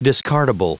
Prononciation du mot discardable en anglais (fichier audio)
Prononciation du mot : discardable